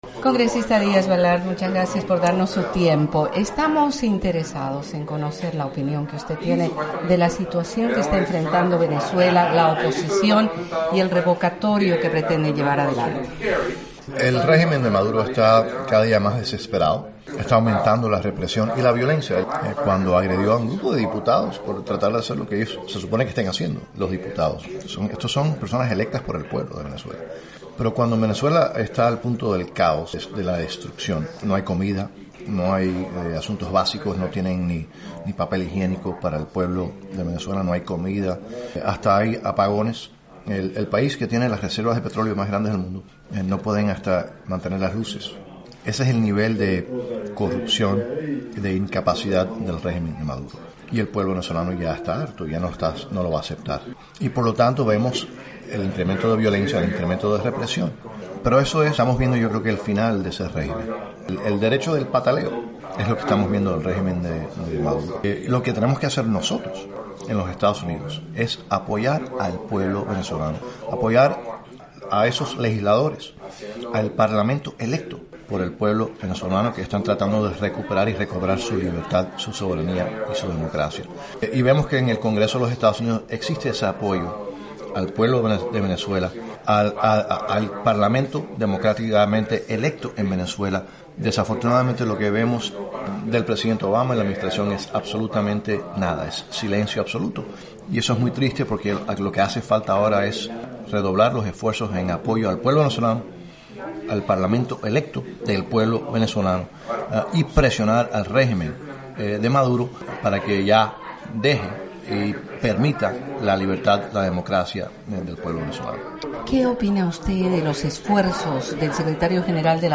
“La crisis de escasez de alimentos y todos los insumos básicos para la sobrevivencia de una persona está alcanzando límites alarmantes en Venezuela y el Congreso de Estados Unidos está consciente de las dificutades que los venezolanos enfrentan cada día”, sostuvo Díaz-Balart en entrevista con la Voz de América.
El congresista Mario Díaz Balart dialoga sobre Venezuela